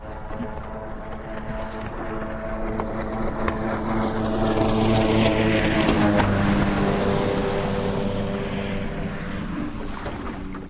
Звуки гидроцикла
На огромной скорости